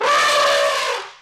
these are two of those elephant trumpets that I'm using.